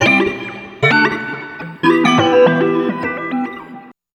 78 GTR 1.wav